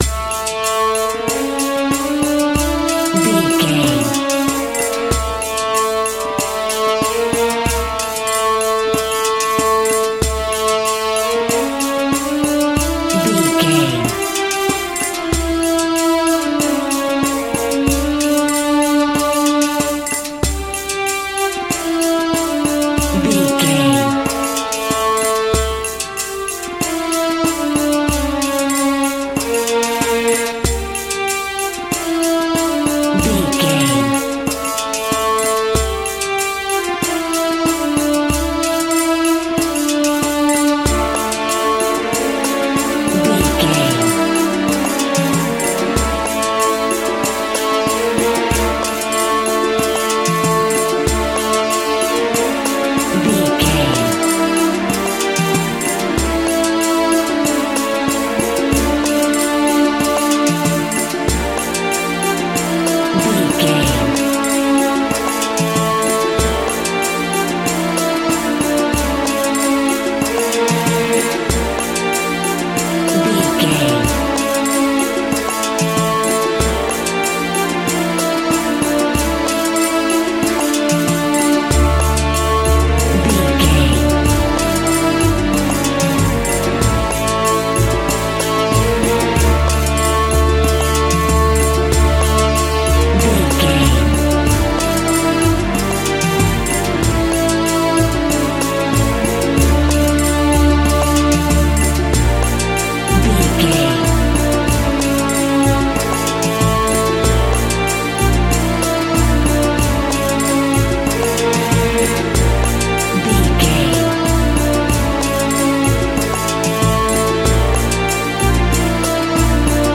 Aeolian/Minor
World Music
percussion
congas
bongos
kora
djembe
kalimba
udu
talking drum
marimba